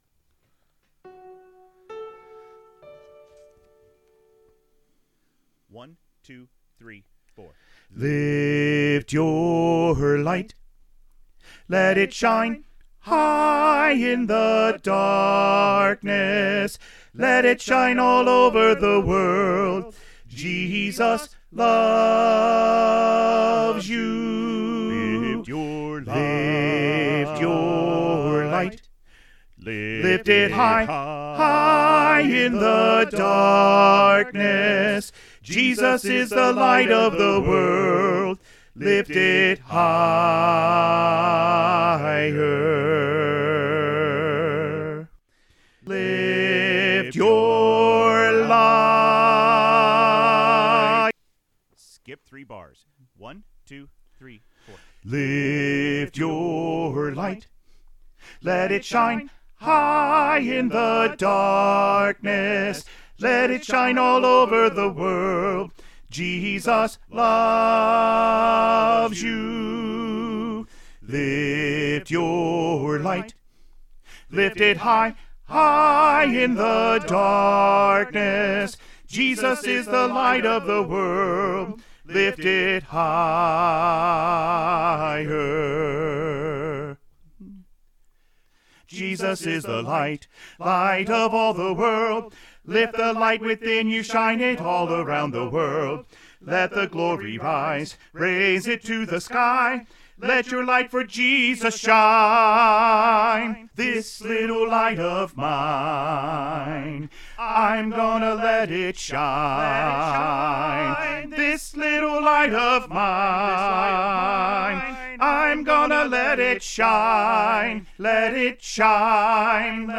Choir Music Learning Recordings
Lift Your Light - Alto Emphasized All 4 Parts with The Alto Part Emphasized